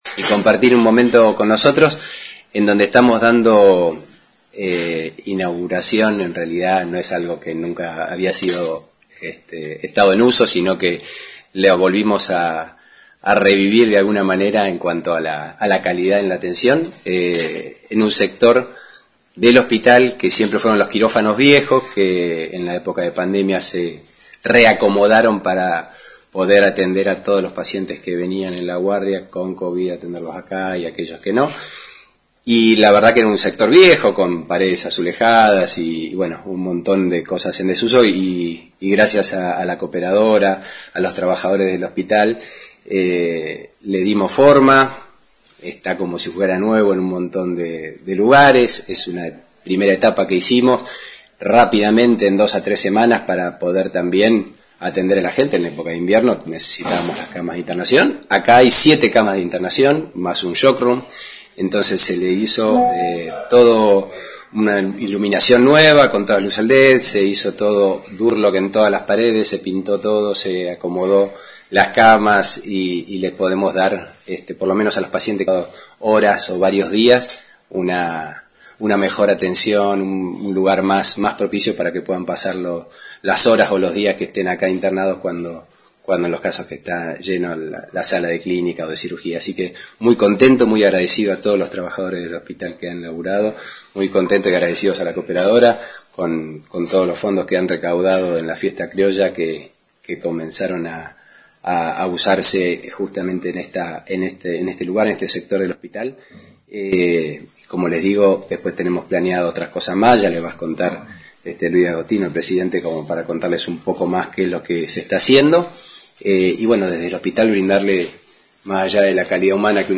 La dirección del Hospital Las Flores junto a autoridades de la Asociación Cooperadora del nosocomio presentaron este lunes a la prensa local las obras de refacción de la sala de internación (ex-quirófano).
Hospital-conferencia.mp3